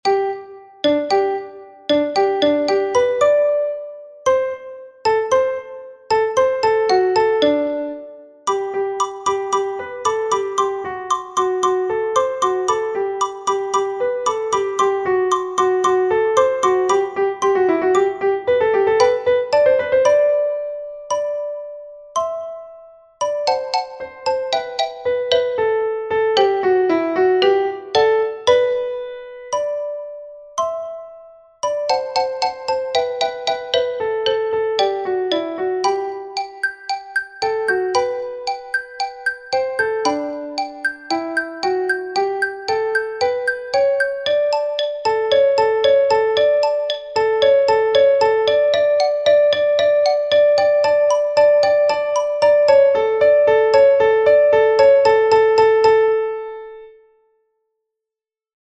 Also take into account the syncopated notes.
Watch out! the score has an Allegro marking, so you have to rehearse enough to get to the right speed (115-143 beats per minute).
Eine_Kleine_Nachtmusik_sincopas.mp3